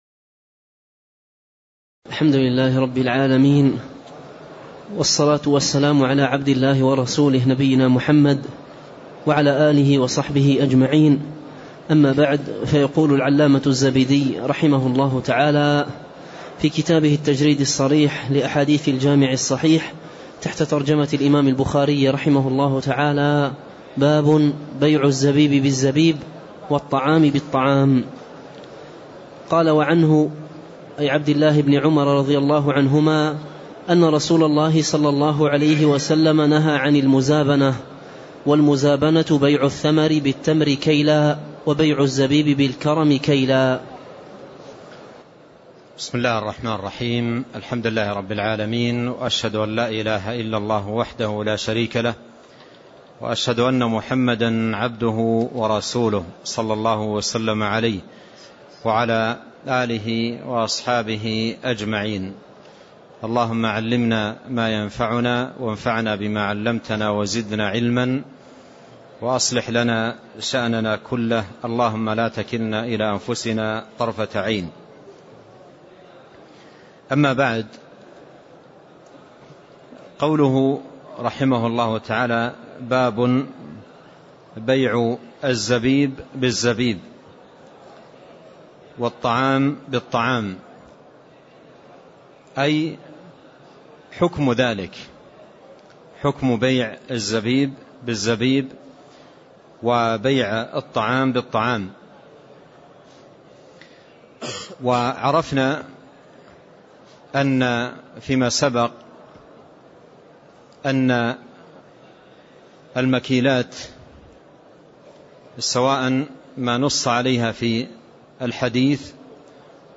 تاريخ النشر ٨ محرم ١٤٣٥ هـ المكان: المسجد النبوي الشيخ